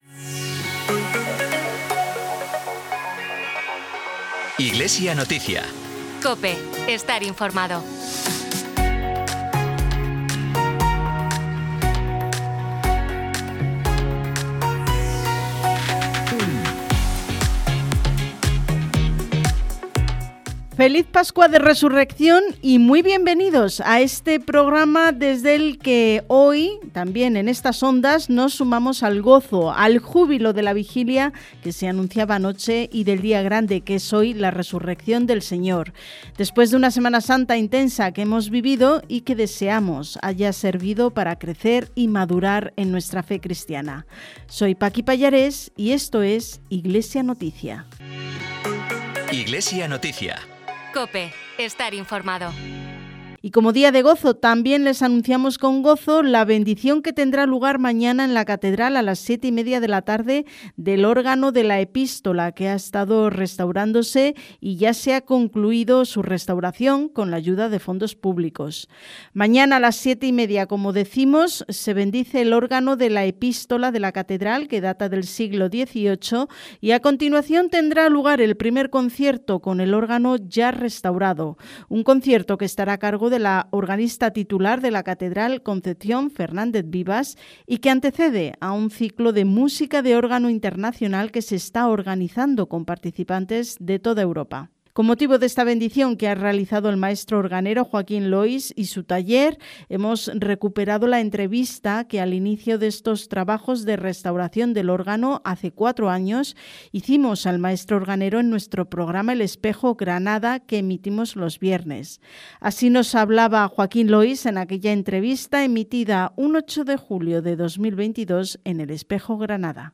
Programa emitido en COPE Granada y COPE Motril, el Domingo de Resurrección, el 5 de abril de 2026.